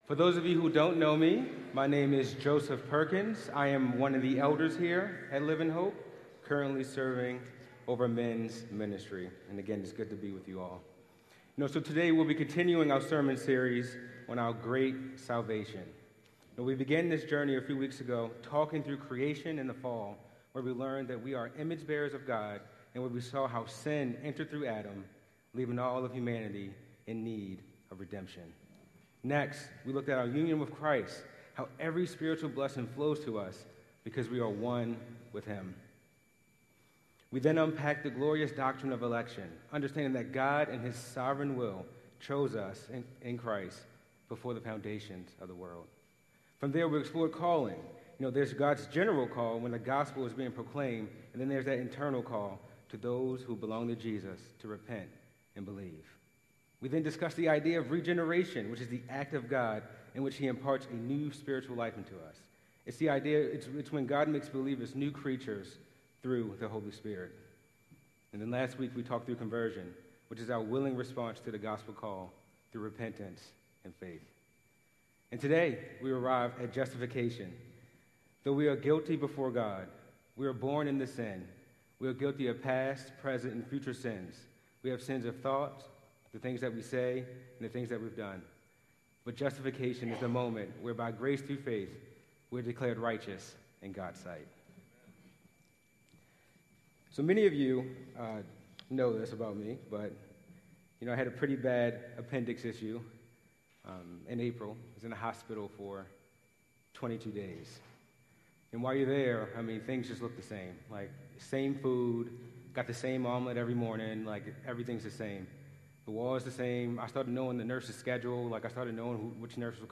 July 20, 2025 Worship Service Order of Service: